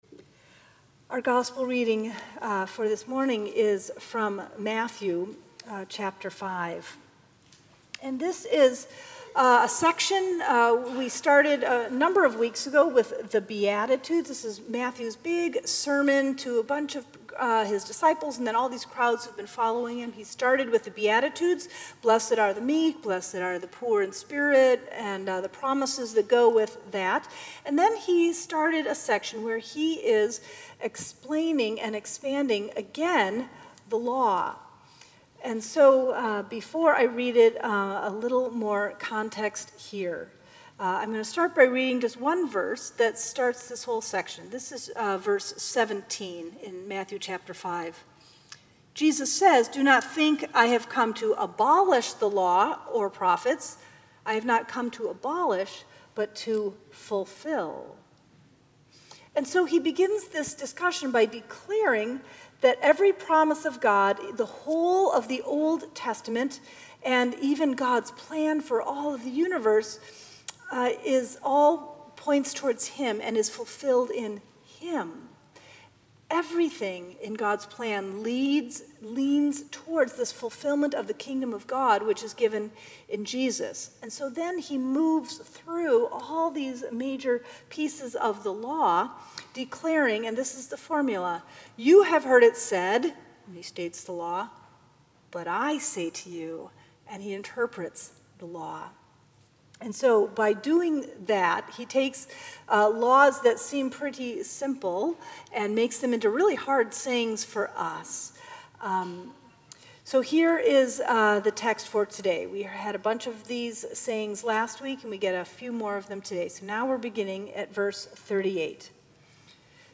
Sermons at Union Congregational Church
February 19, 2017 Seventh Sunday after Epiphany